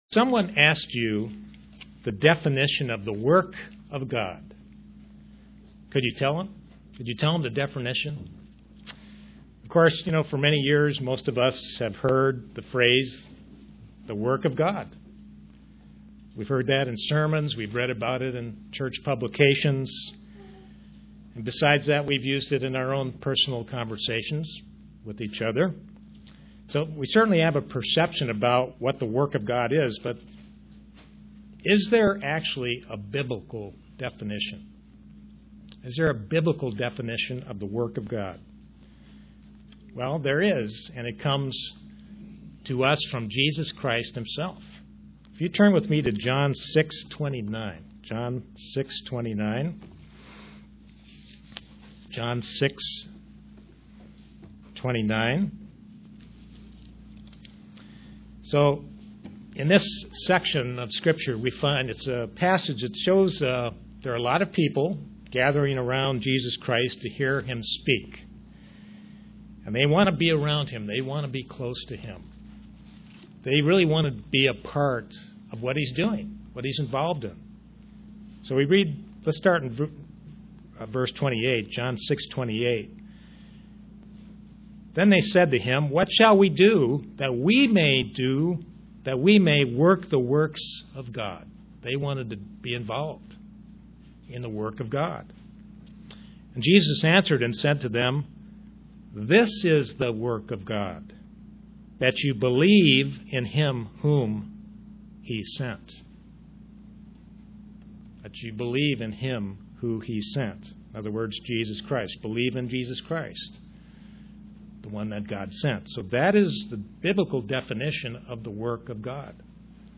Print Obeying God and His way is the work of God UCG Sermon Studying the bible?